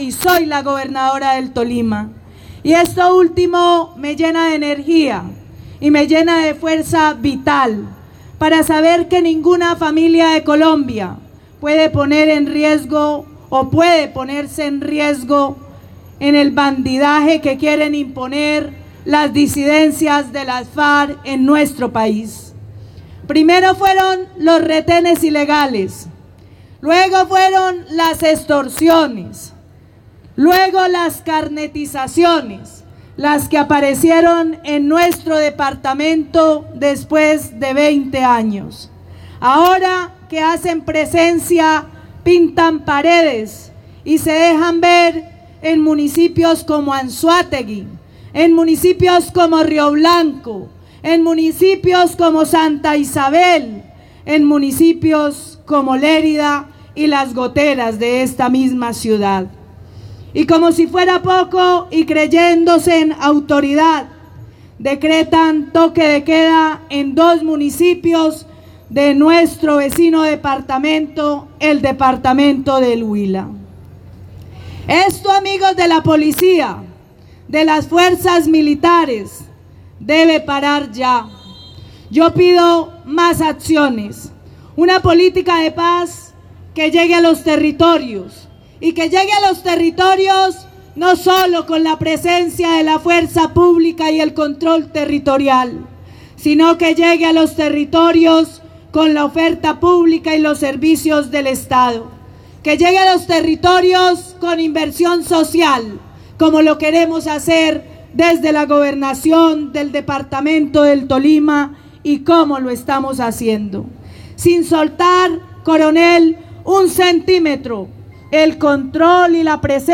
Durante una ceremonia de Juramento de Bandera de nuevos integrantes de la Policía Nacional en Ibagué, la gobernadora del Tolima, Adriana Magali Matiz, hizo un llamado a la Fuerza Pública a mantenerse firmes en la defensa del territorio y exigió una mayor inversión por parte del Estado.
Escuche las declaraciones de la Gobernadora Adriana Magaly Matiz: